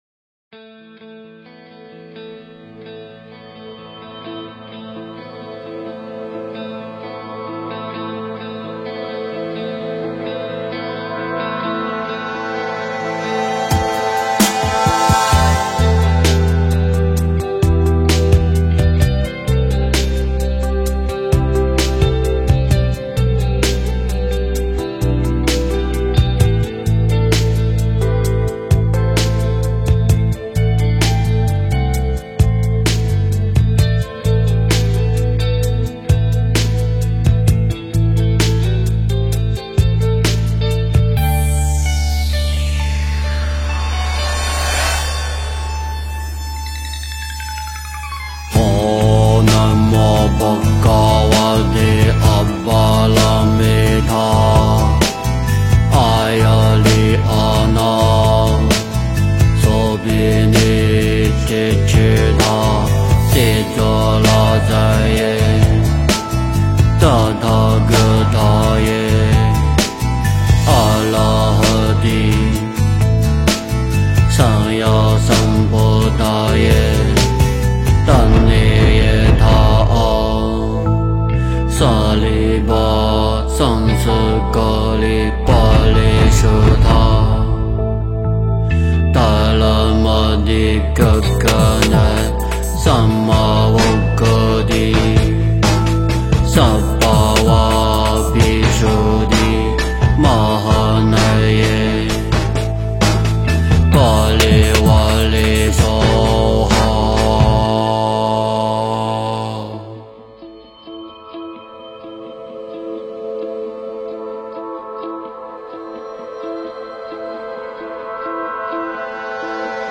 诵经
佛音 诵经 佛教音乐 返回列表 上一篇： 三皈依 下一篇： 心经 相关文章 观音菩萨偈圣号--佛音 观音菩萨偈圣号--佛音...